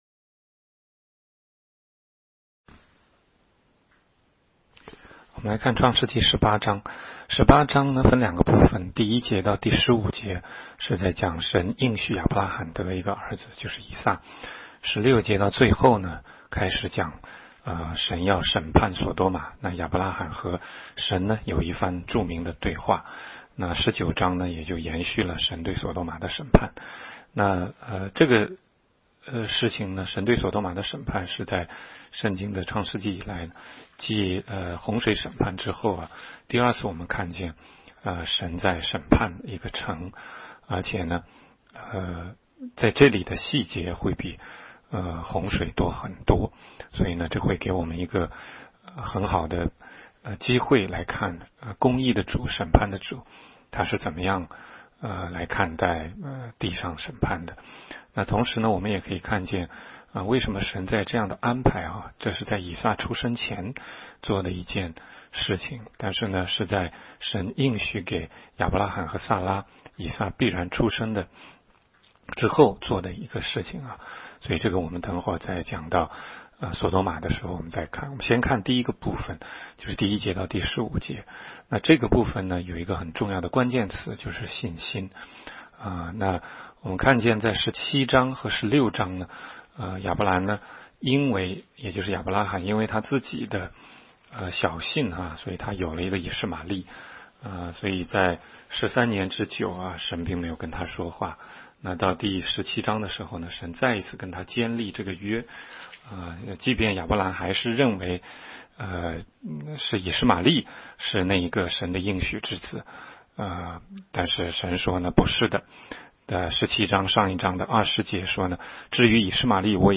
16街讲道录音 - 每日读经-《创世记》18章